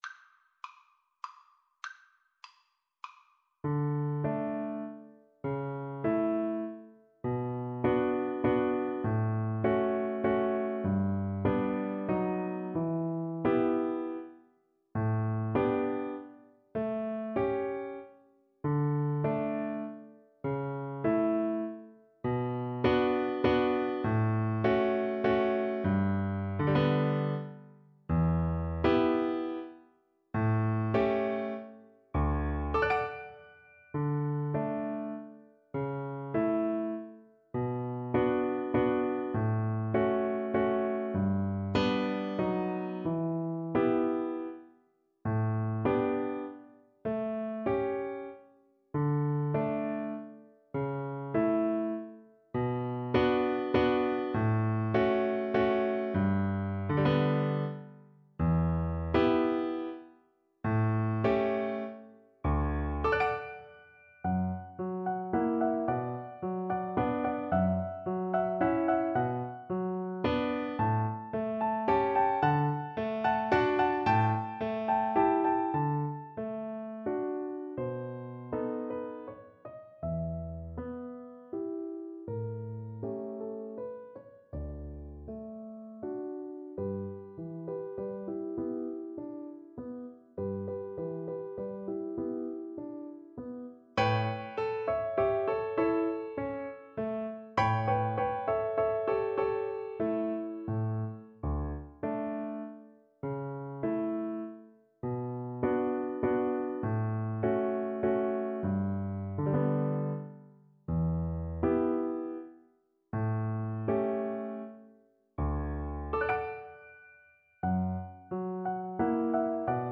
~ = 100 Tranquillamente
3/4 (View more 3/4 Music)